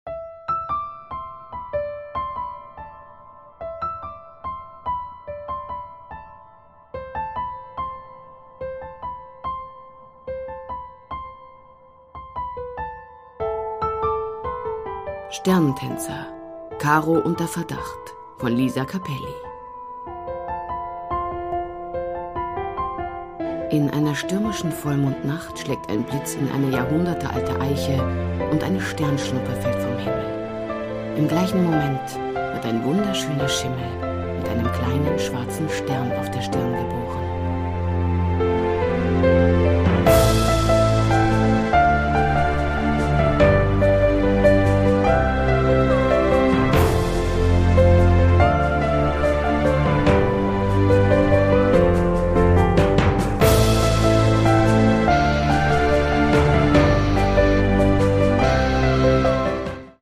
Produkttyp: Hörbuch-Download
Fassung: gekürzte Fassung